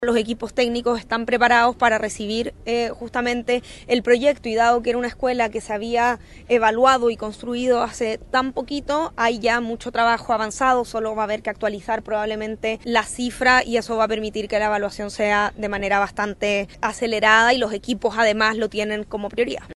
El proyecto y los fondos deben ser aprobados por el Ministerio de Desarrollo Social, cuya titular, Javiera Toro, aseguró todo el esfuerzo para un trámite expedito.